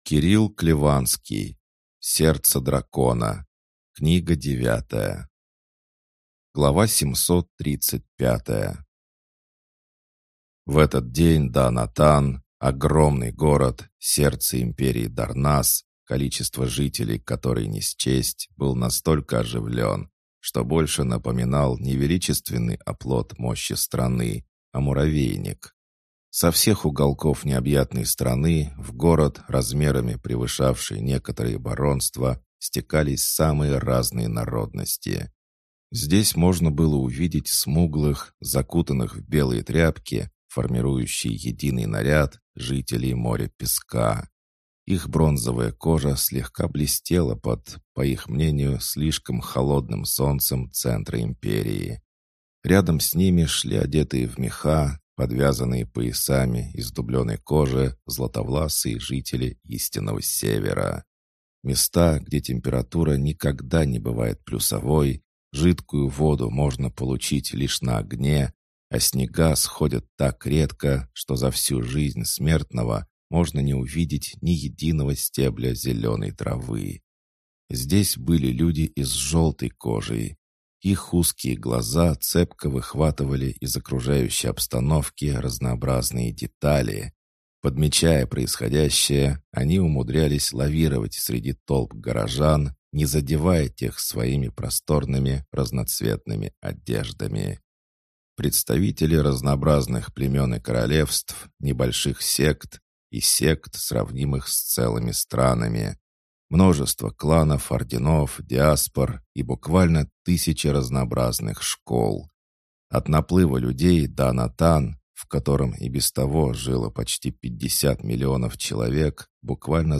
Аудиокнига Сердце Дракона. Книга 9 | Библиотека аудиокниг